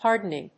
音節hard・en・ing 発音記号・読み方
/hάɚd(ə)nɪŋ(米国英語), hάːd(ə)nɪŋ(英国英語)/